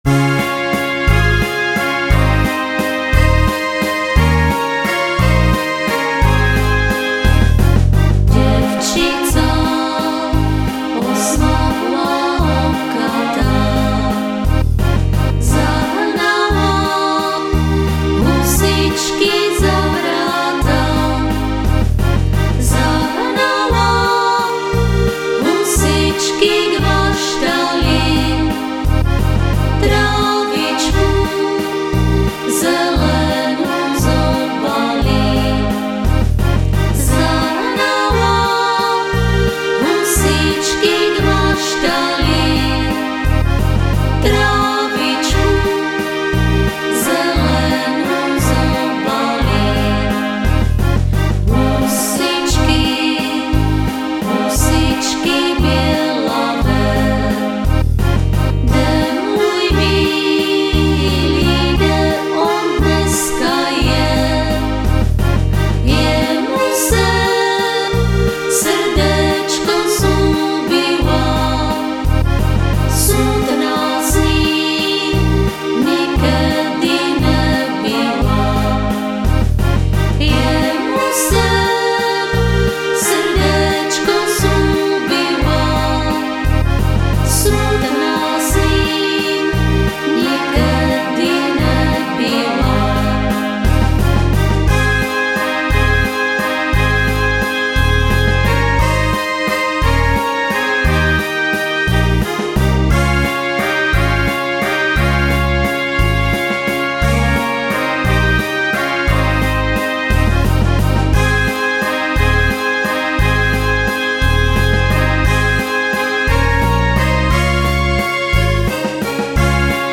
CD3 - pekný valčík pre ženský hlas...